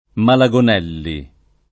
Malegonnelle [ male g onn $ lle ] o Malegonelle [ male g on $ lle ] cogn.